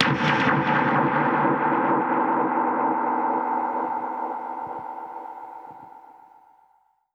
Index of /musicradar/dub-percussion-samples/134bpm
DPFX_PercHit_A_134-07.wav